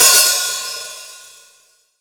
Index of /90_sSampleCDs/AKAI S6000 CD-ROM - Volume 3/Hi-Hat/STUDIO_HI_HAT